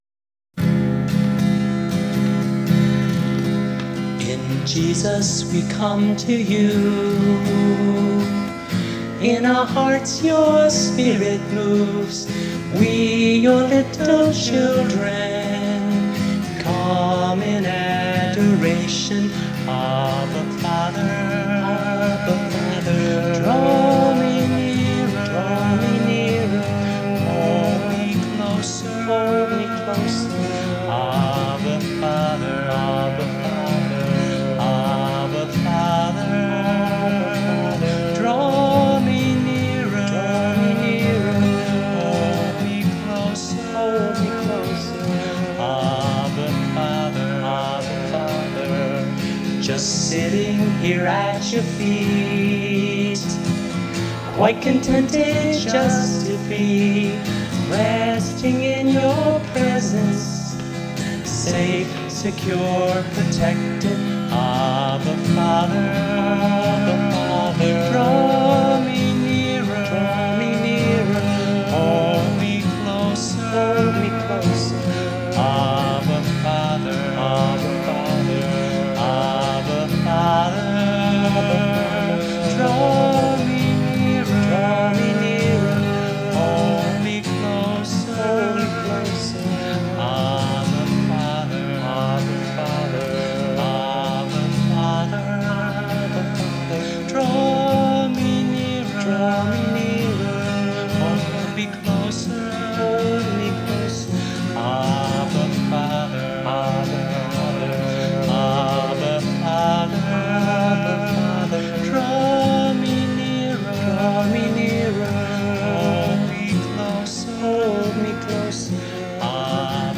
a spiritual intimacy song